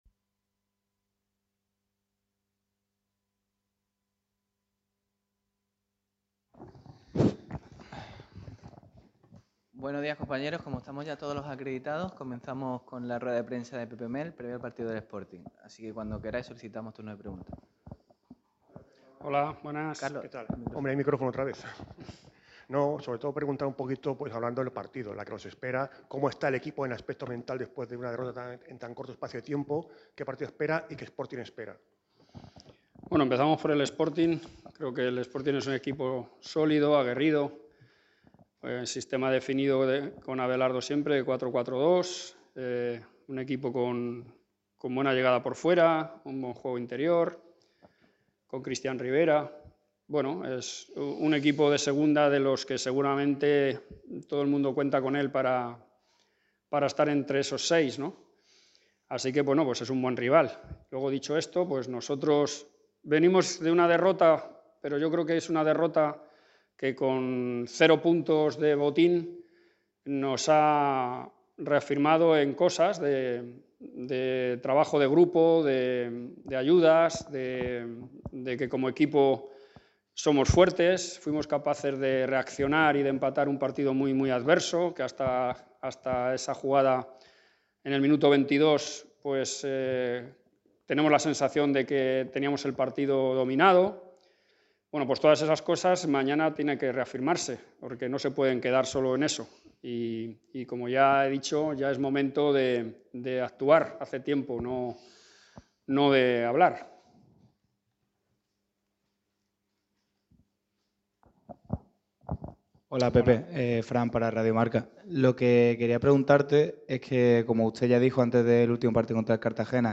El entrenador del Málaga ha comparecido en rueda de prensa este sábado como previa al partido ante el Sporting. El madrileño refuerza el trabajo del equipo y analiza algún que otro nombre propio.